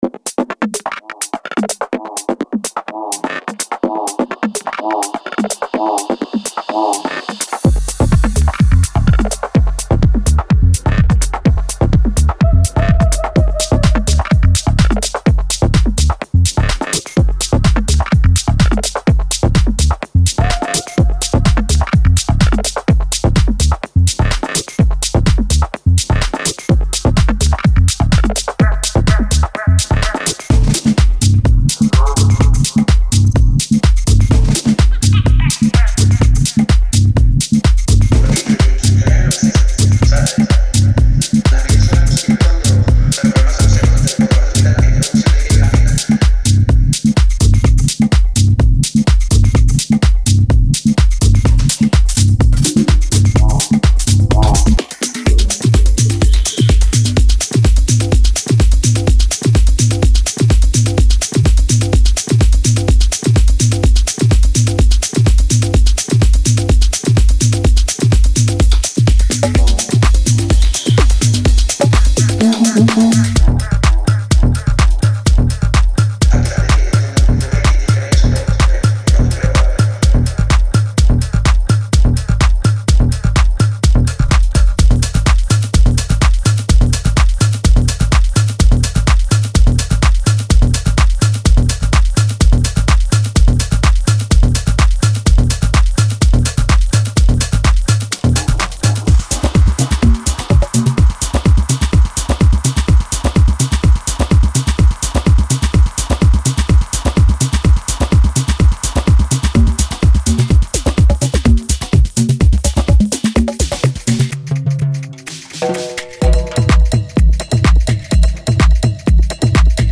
Genre:Tech House
36 Conga Loops
33 Bass Loops